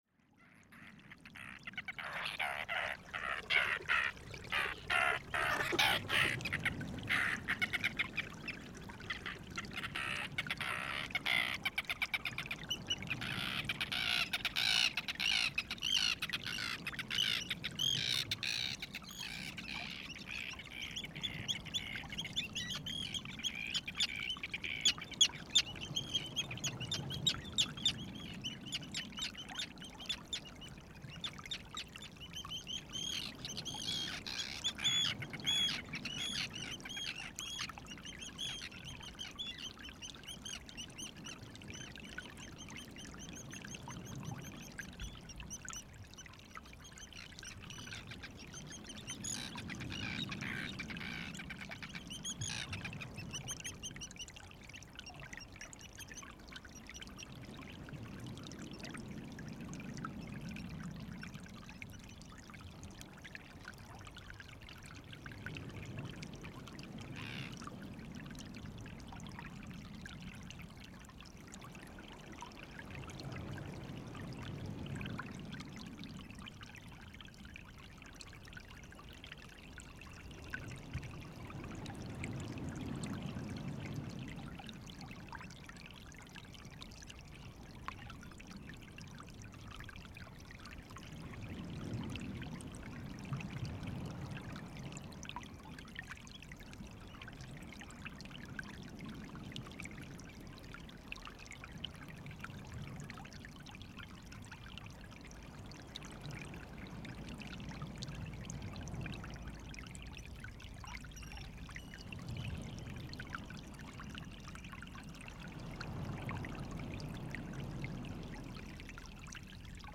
Hér er á ferðinni lágstemmd hljóðupptaka sem beinlínis lýsir ljóðrænni sveitasælu út við sjó. Hún er tekin upp við Kópavog fyrir neðan bæinn Ófeigsfjörð við samnefndan fjörð á Ströndum. Ímyndaðu þér að þú liggir þar með lokuð augun á grasbala rétt ofan við fjöruna við hliðina á lækjarsprænu sem rennur þar til sjávar.
Lágstemmd en þung undiraldan lemur sandfjöruna fyrir neðan þig og þú heyrir í henni jafn vel neðan úr jörðinni sem ofan.